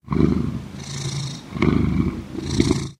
purr1.ogg